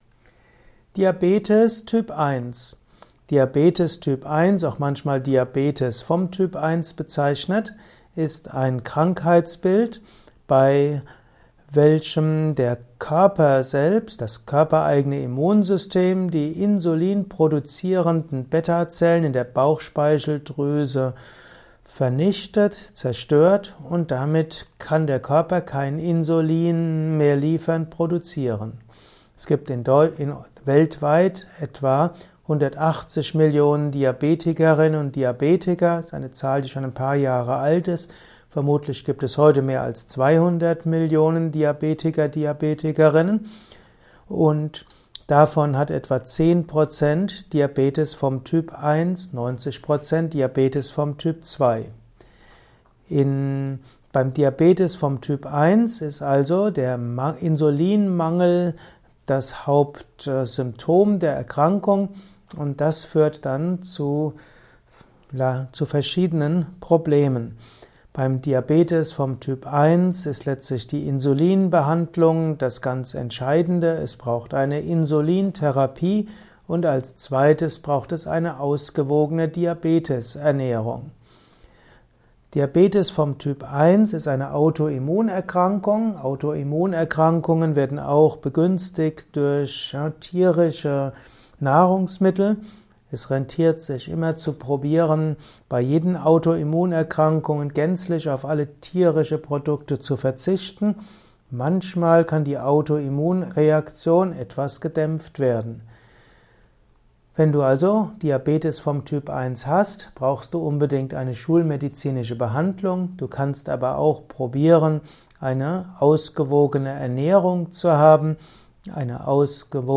Ein Kurzvortrag über die Krankheit Diabetis Typ 1